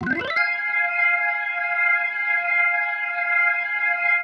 Oakland Organ_1.wav